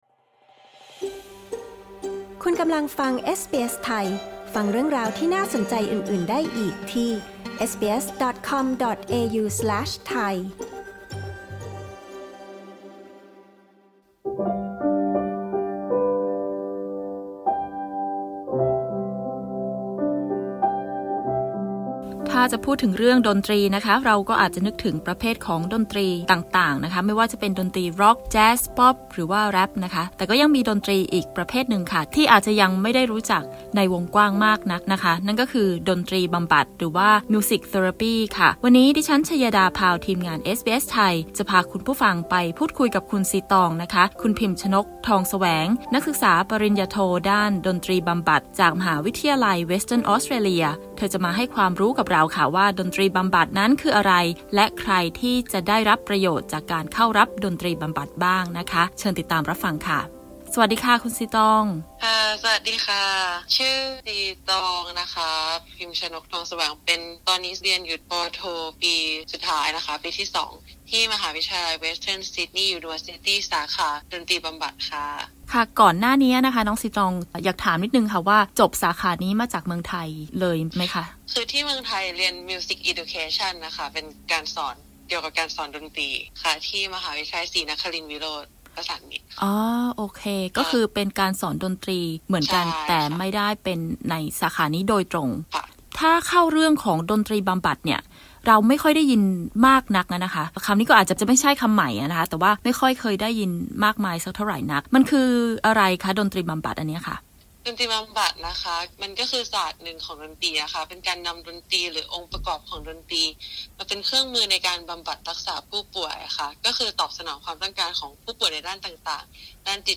ฟังการสัมภาษณ์เรื่องดนตรีบำบัดที่นี่